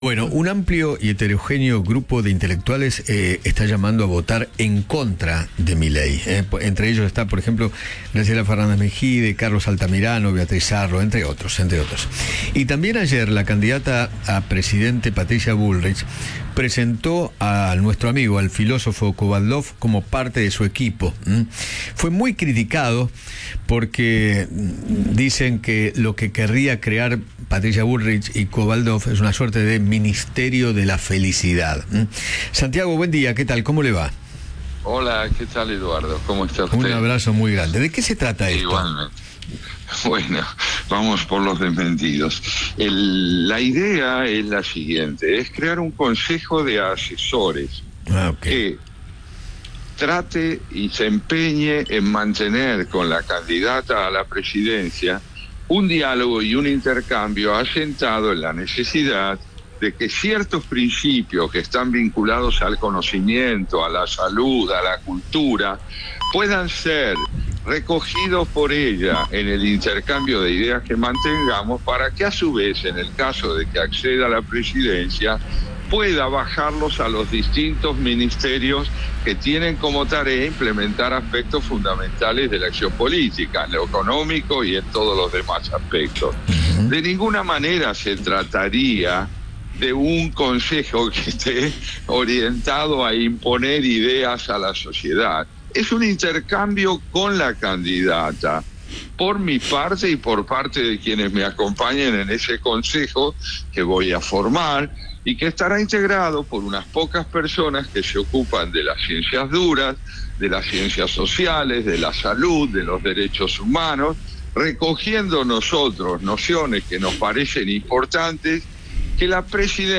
Santiago Kovadloff, filósofo y ensayista, conversó con Eduardo Feinmann sobre la camapaña de Patricia Bullrich, tras ser confirmado como integrante de su equipo.